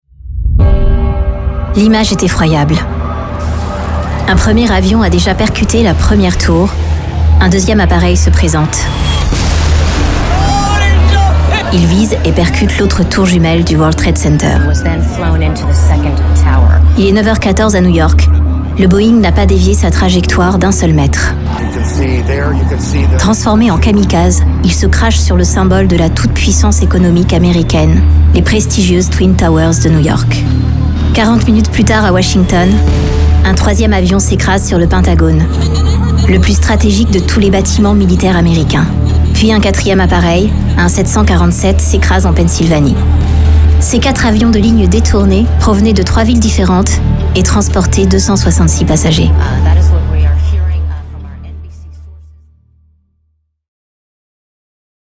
Voix off
28 - 44 ans - Mezzo-soprano